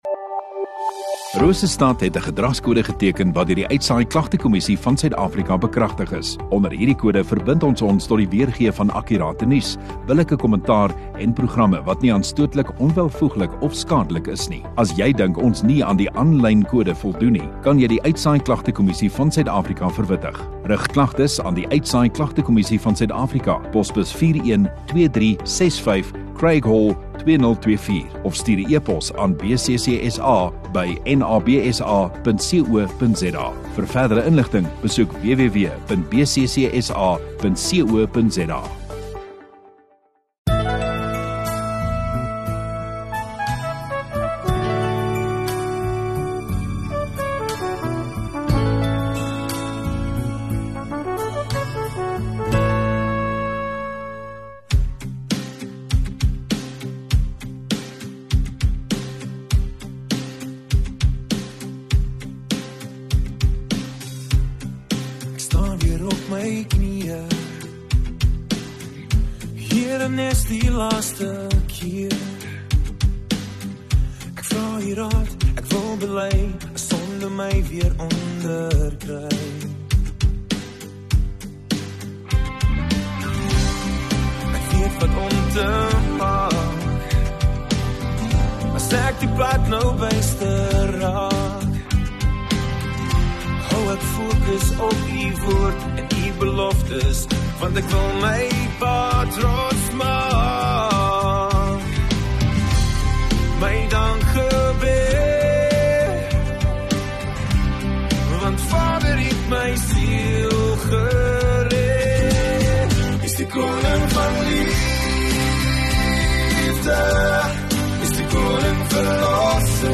12 Jul Saterdag Oggenddiens